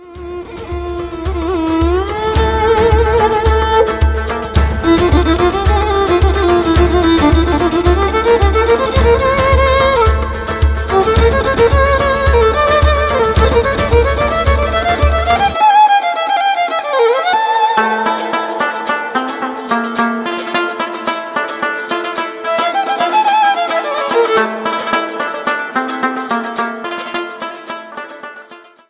Category: Arabic Ringtones